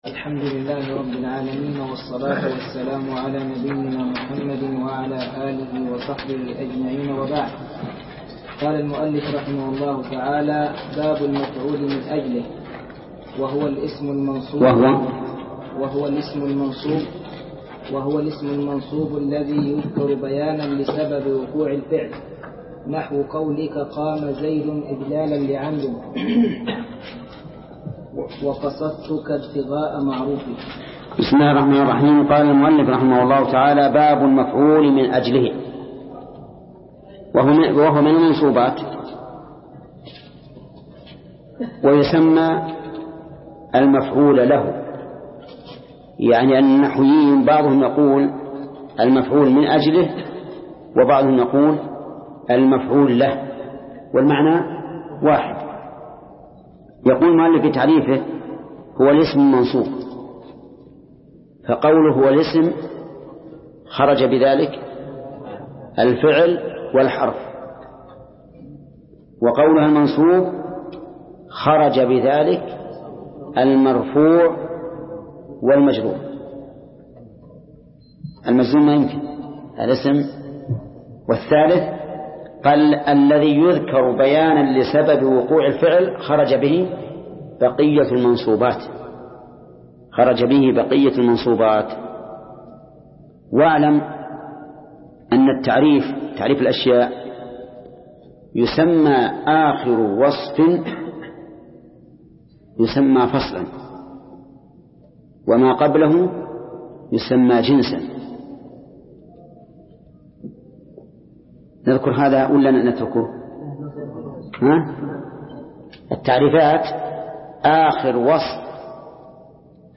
درس (24) : شرح الآجرومية : من صفحة: (497)، قوله: (باب المفعول من أجله).، إلى صفحة: (521)، قوله: (باب مخفوضات الأسماء)